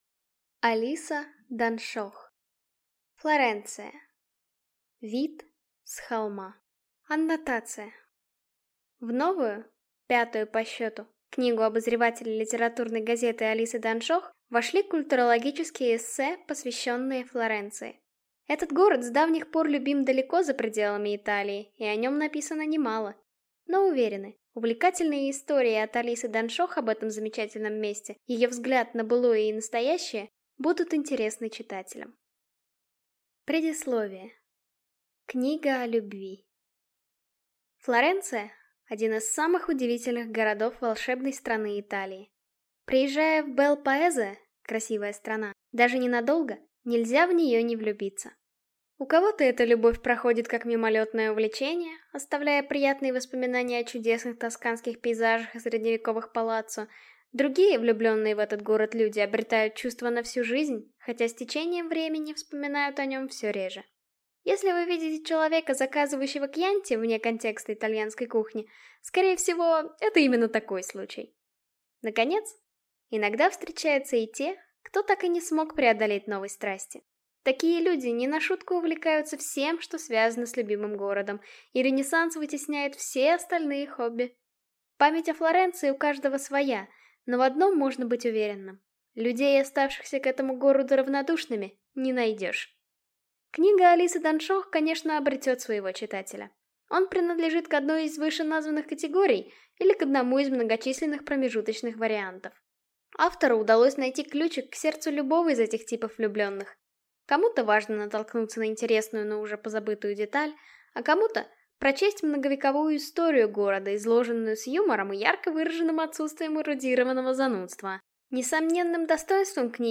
Аудиокнига Флоренция. Вид с холма | Библиотека аудиокниг